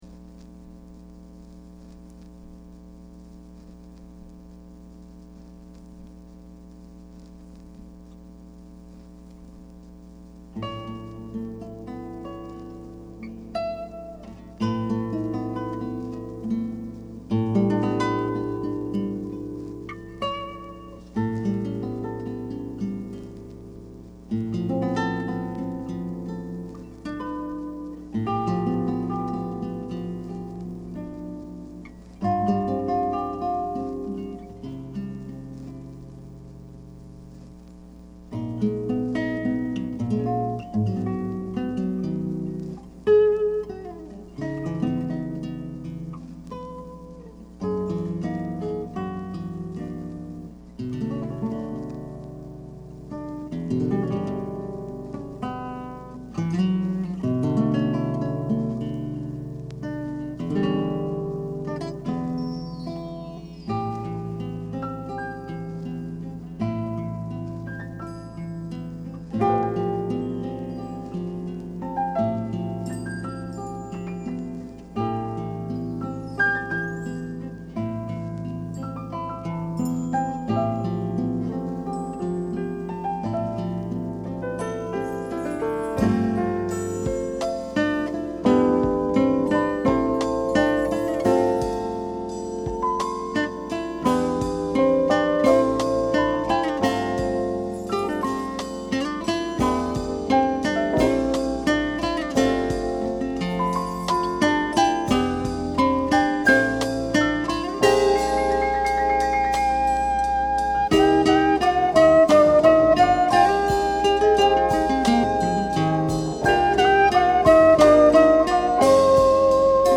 Genre: Jazz Fusion / Smooth Jazz
1978年5月13日、ロサンゼルスのFilmways/Heiderスタジオで収録。
マスターテープを介さず直接カッティングするため、音の立ち上がりと透明感が際立つ。